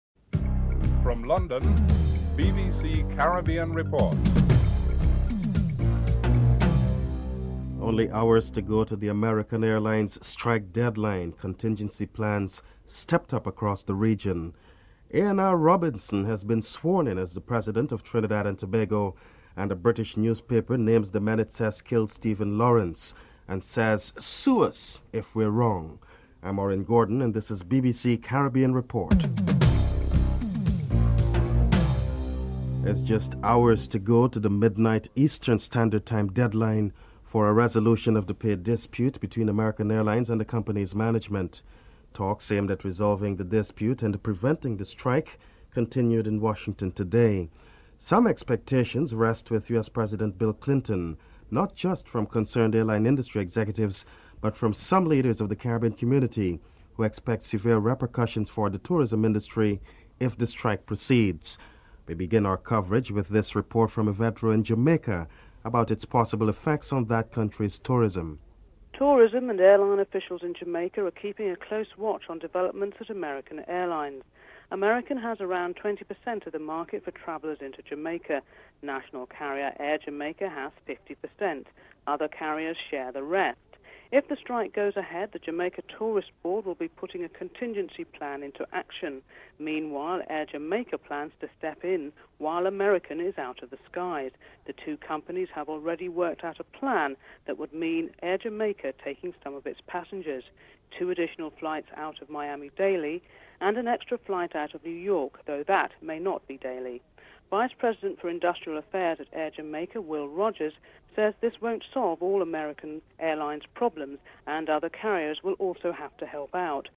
1. Headlines (00:00-00:31)
Vincent Vanderpool Wallace, Director General of Tourism, Bahamas is interviewed (00:32-04:54)